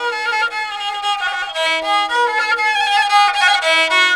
Index of /90_sSampleCDs/Sonic Foundry (Sony Creative Software) - World Pop/Stringed Instruments/Chinese